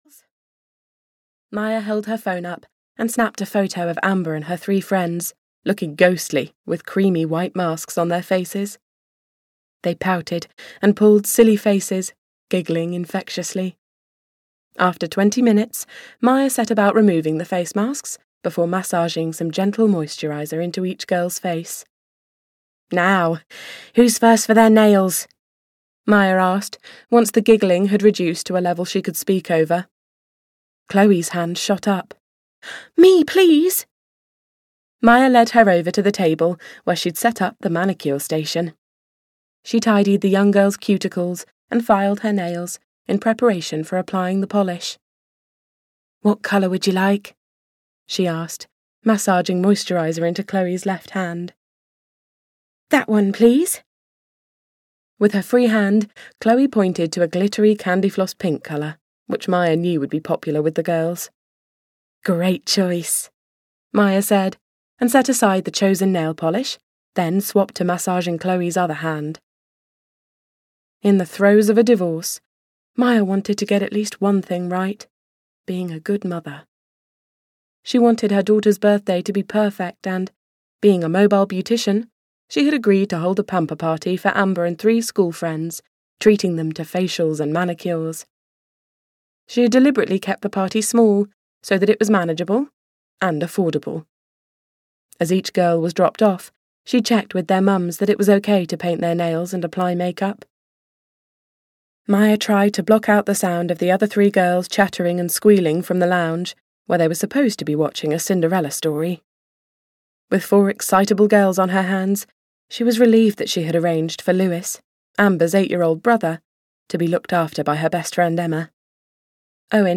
Sunsets and Happy Ever Afters (EN) audiokniha
Ukázka z knihy